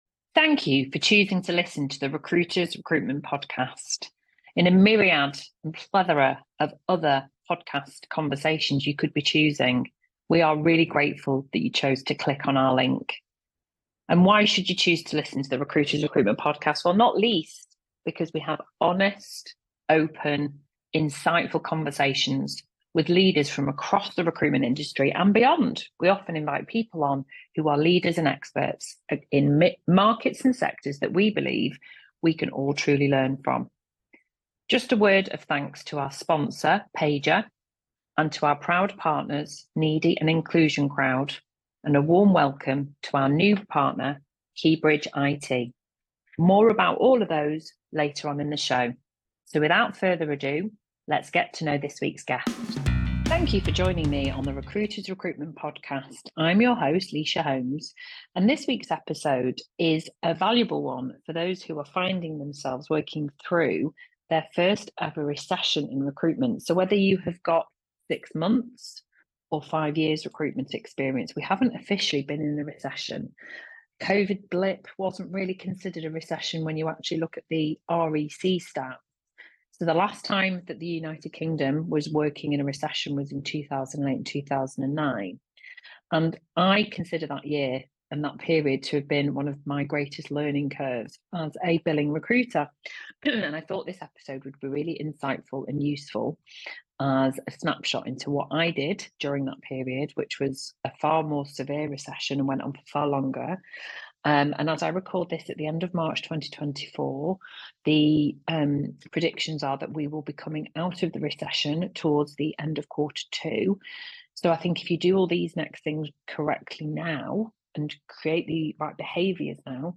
In this week's short solo episode,